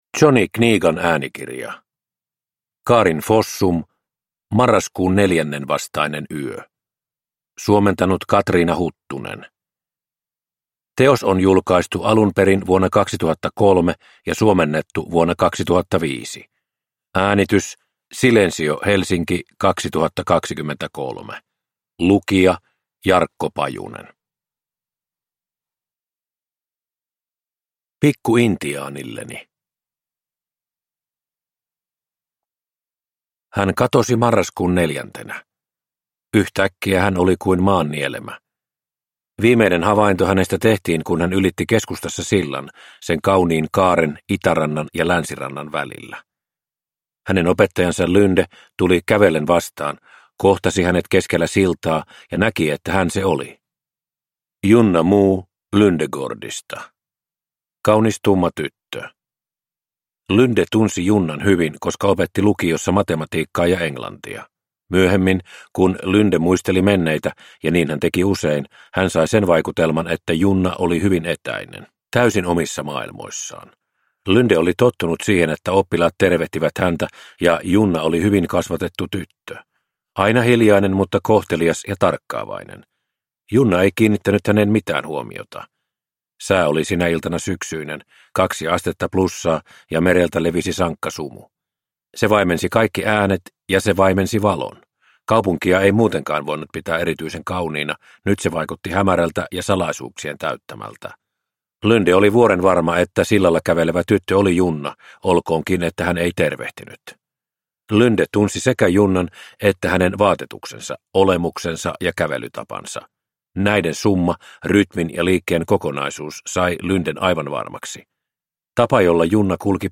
Marraskuun neljännen vastainen yö – Ljudbok – Laddas ner